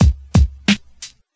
• 88 Bpm Old School Hip-Hop Breakbeat C# Key.wav
Free breakbeat sample - kick tuned to the C# note. Loudest frequency: 887Hz
88-bpm-old-school-hip-hop-breakbeat-c-sharp-key-Q5v.wav